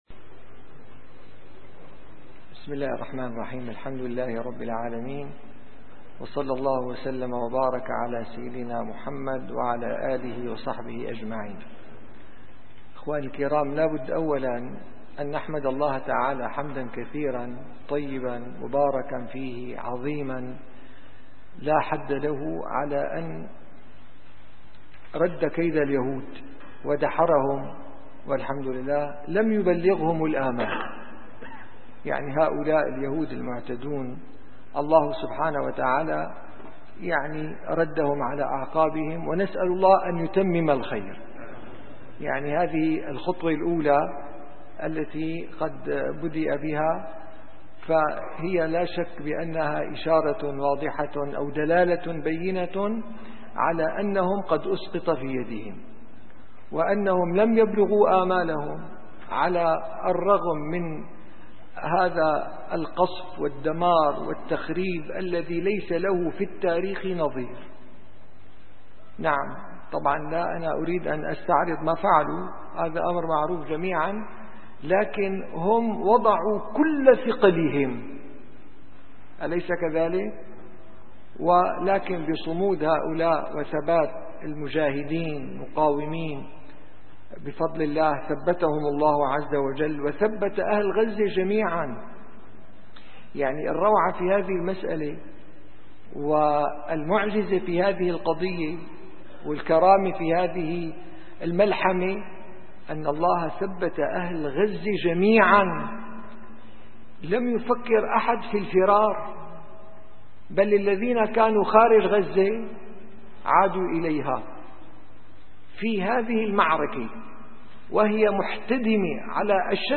- الدروس العلمية - شرح صحيح البخاري - كتاب الجنائز الحديث 1362